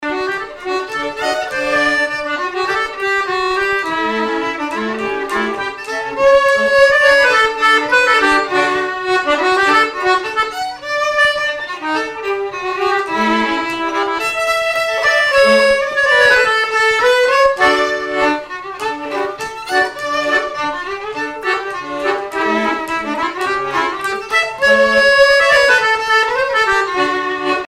danse : quadrille
à l'accordéon chromatique
au violon
Pièce musicale inédite